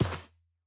Minecraft Version Minecraft Version snapshot Latest Release | Latest Snapshot snapshot / assets / minecraft / sounds / mob / camel / step_sand3.ogg Compare With Compare With Latest Release | Latest Snapshot
step_sand3.ogg